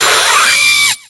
Cri de Fermite dans Pokémon X et Y.